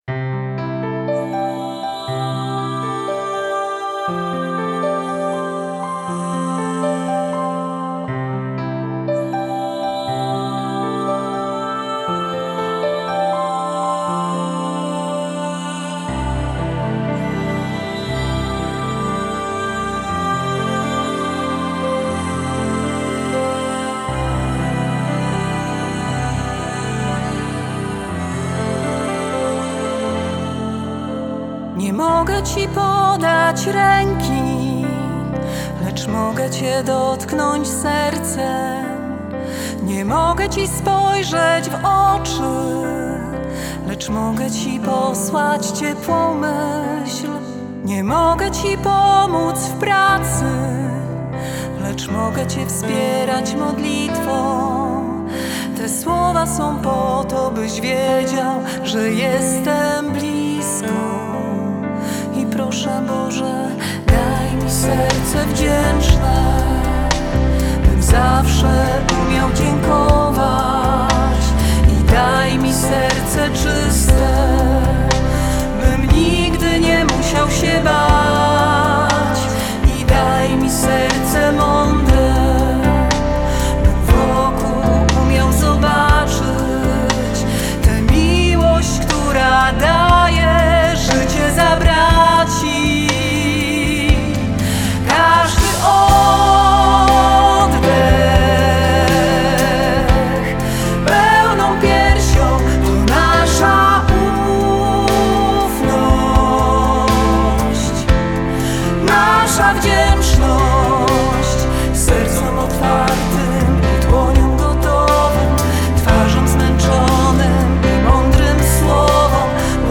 Siostry Karmelitanki nagrały piosenkę dziękczynną